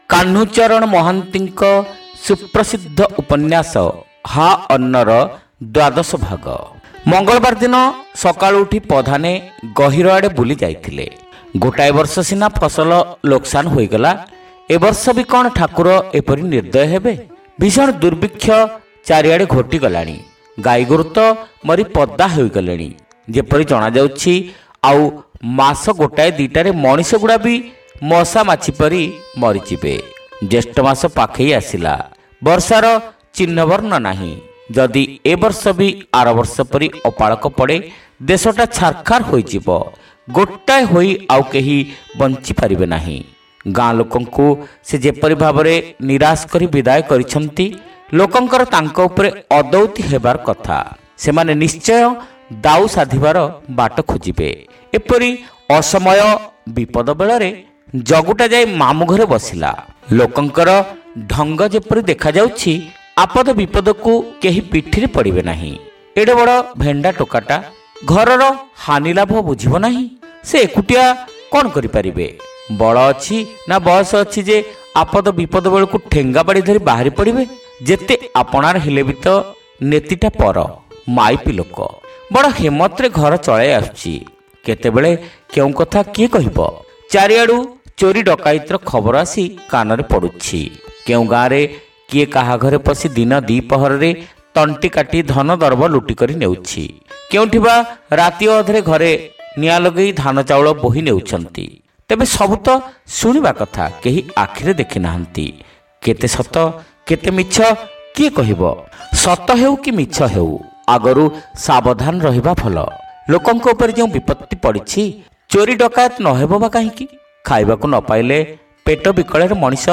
ଶ୍ରାବ୍ୟ ଉପନ୍ୟାସ : ହା ଅନ୍ନ (ଦ୍ଵାଦଶ ଭାଗ)